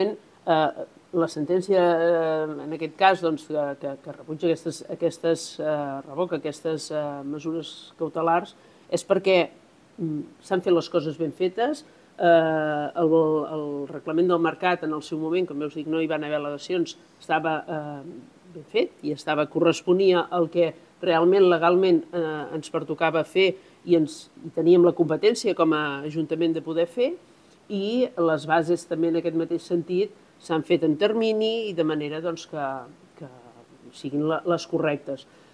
Finalment, va decidir reorganitzar el mercat atenent les circumstàncies del municipi. Són declaracions de l’alcaldessa accidental de Castell d’Aro, Platja d’Aro i S’Agaró, Imma Gelabert.